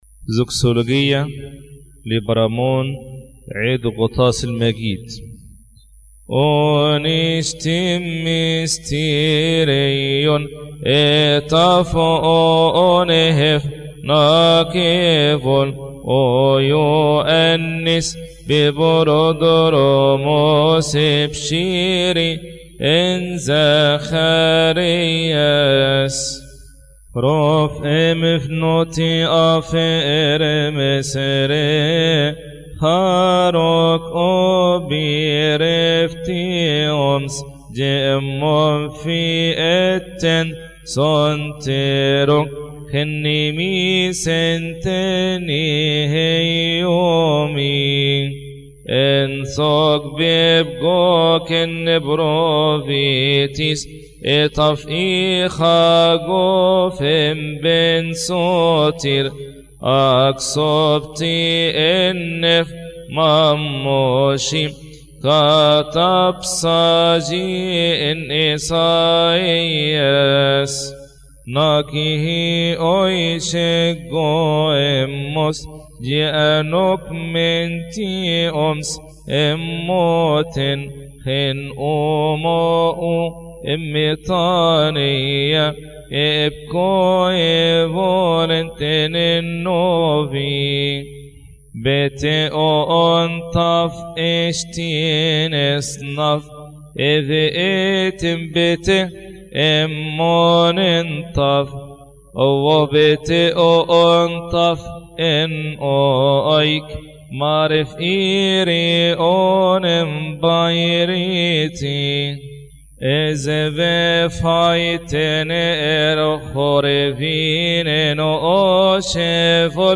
المرتل
للمرتل